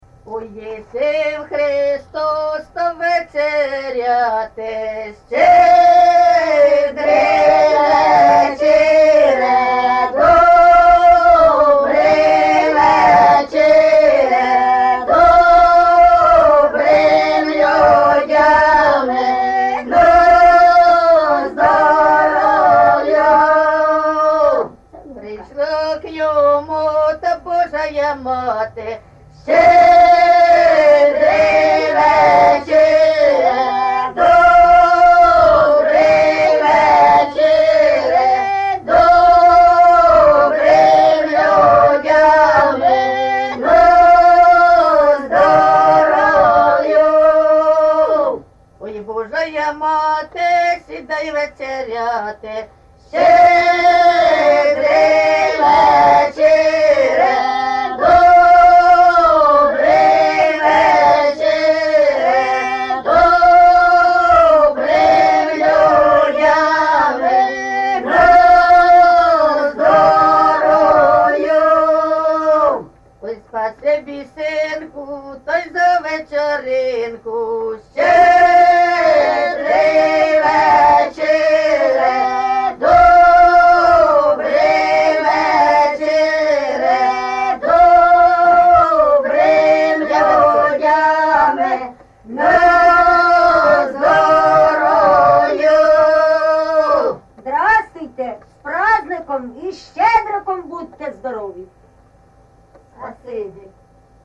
ЖанрЩедрівки
Місце записус. Лука, Лохвицький (Миргородський) район, Полтавська обл., Україна, Полтавщина